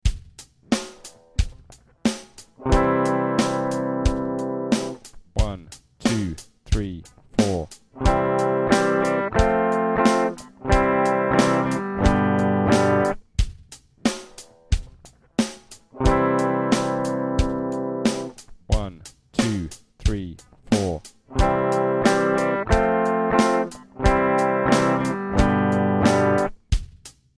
These are a 2 bar chord sequence of I, IV, V chords in any combination (C, F G in the key of C).  Each test will play the tonic (I) chord (C) before the sequence.